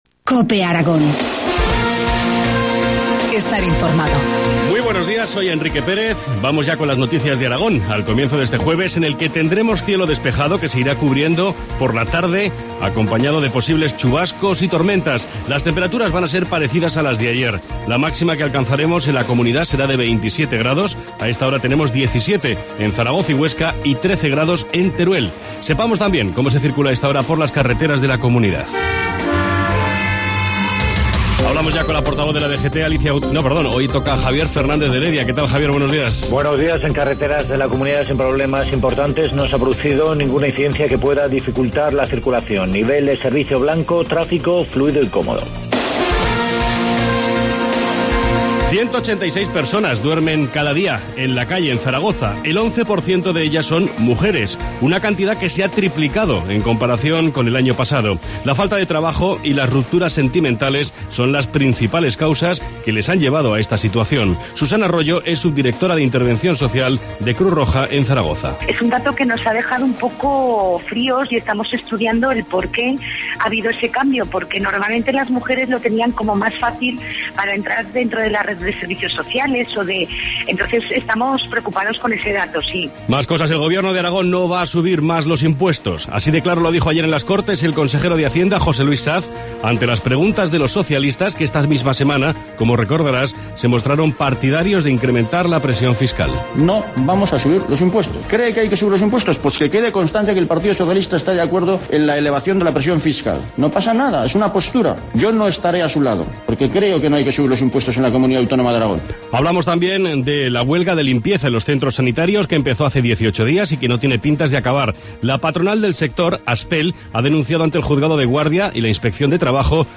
Informativo matinal, Jueves 6 junio 7,25 horas